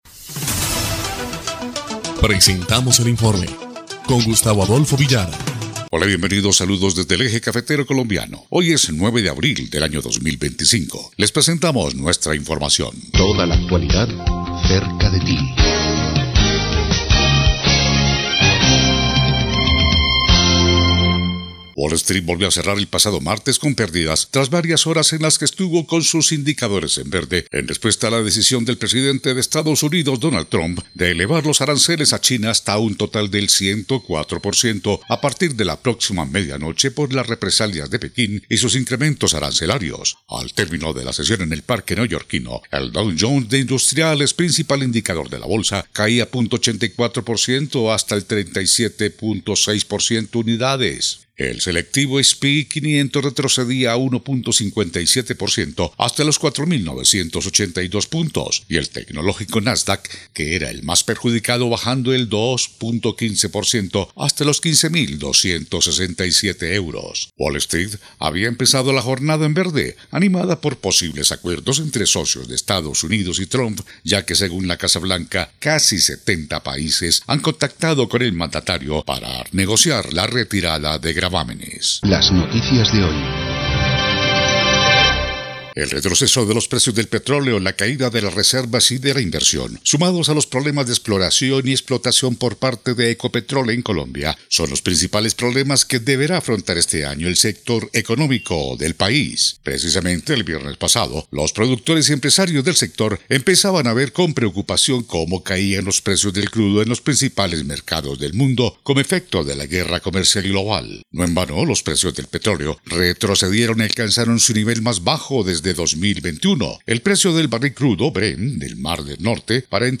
EL INFORME 1° Clip de Noticias del 9 de abril de 2025